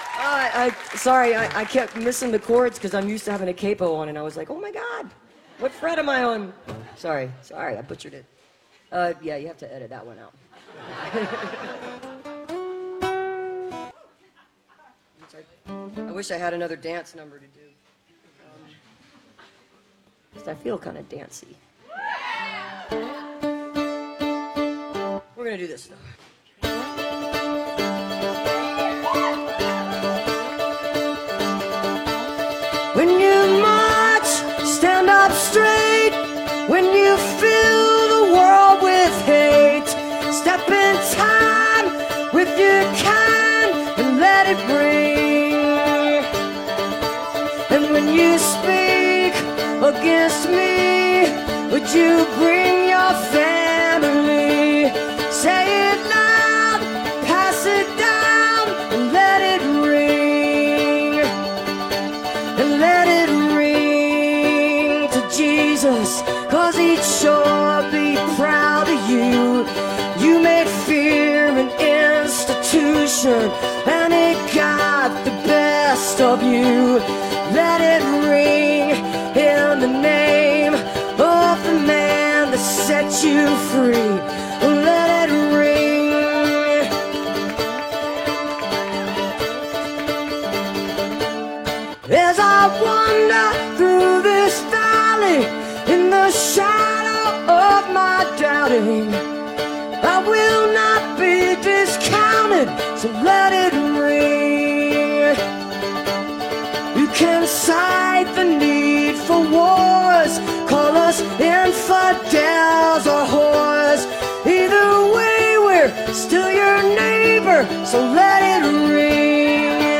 (captured from a youtube video)